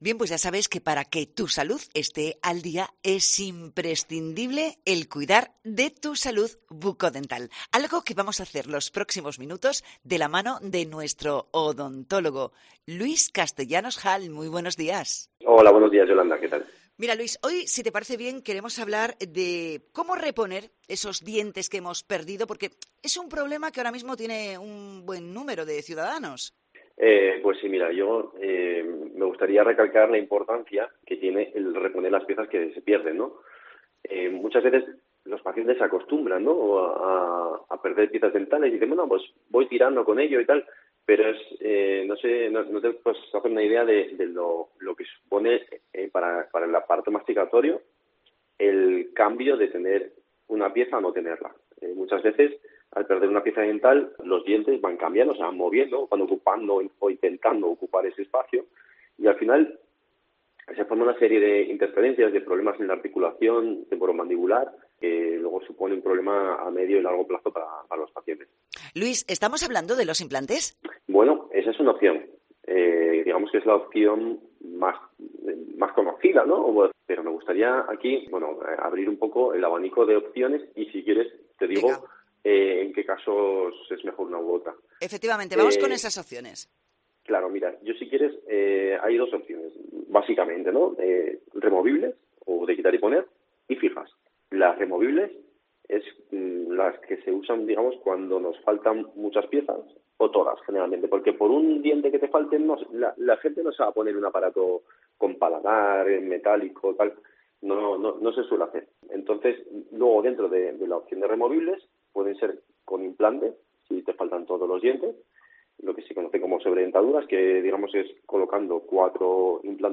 'Salud' al Día en COPE: entrevista